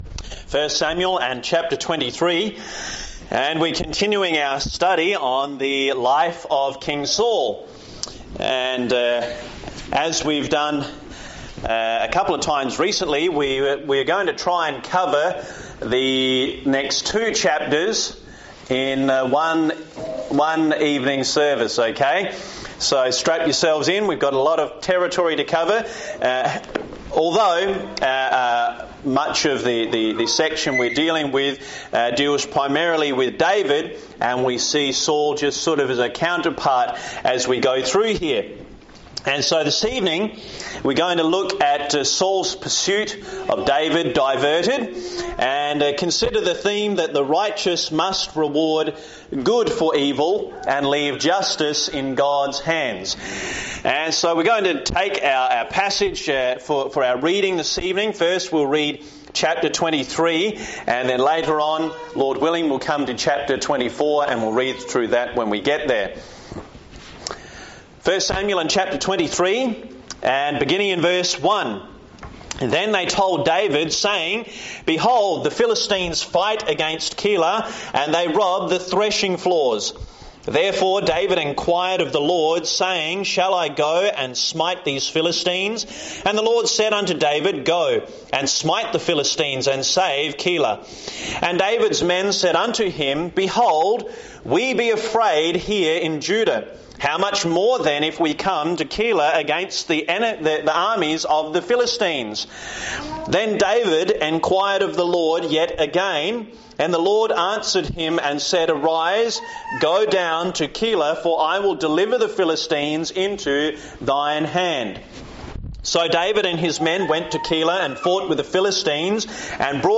This message from I Samuel 23-24 teaches believers to trust God’s timing, reward evil with good, and leave justice in His hands